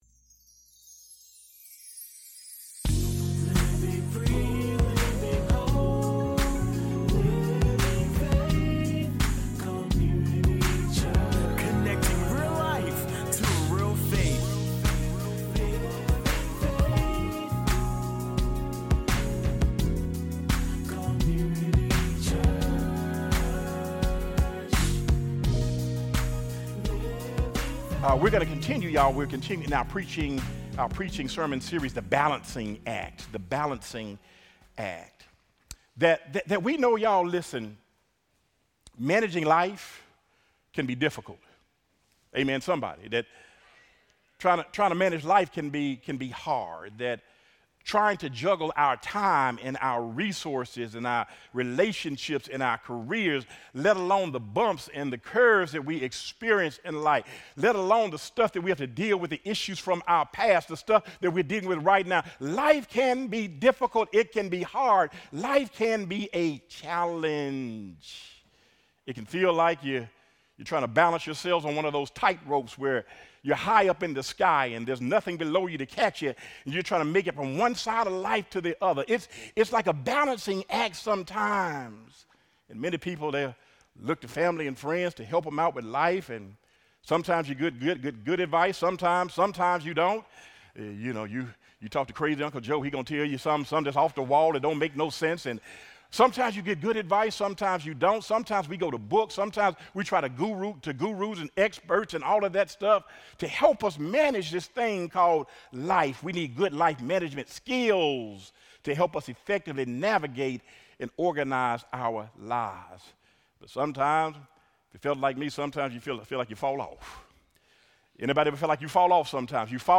Audio Sermons | Living Faith Community Church